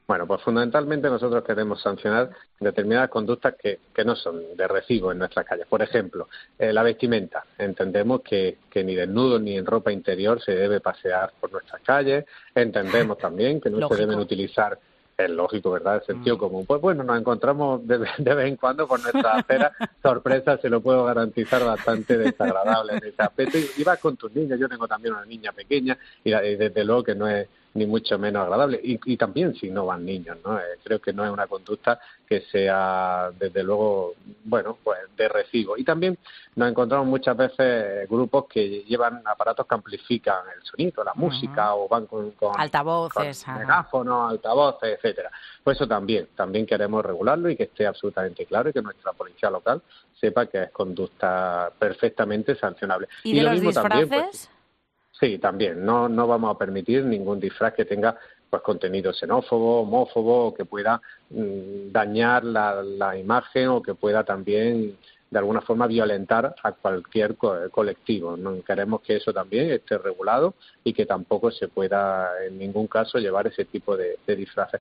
Aquí tienes el audio completo en el que Saavedra da todos los detalles de este cambio de normativa en 'Mediodía COPE'.